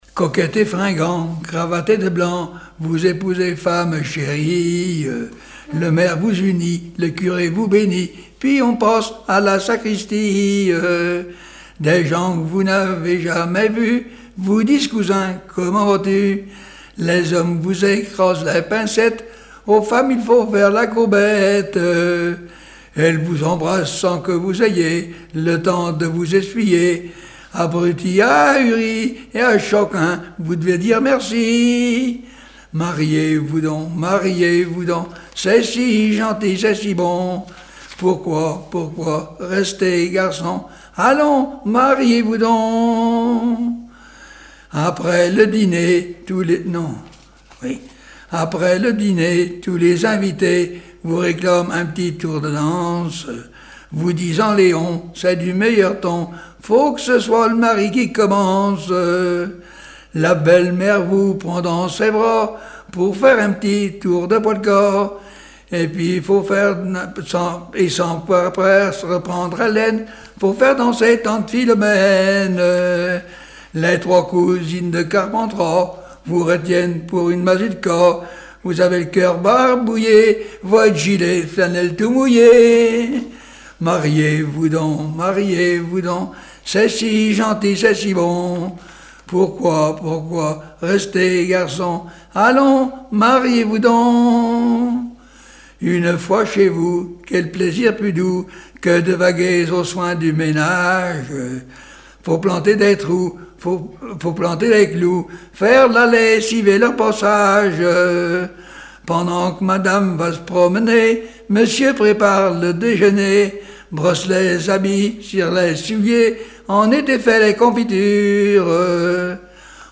Genre strophique
Témoignages et chansons
Pièce musicale inédite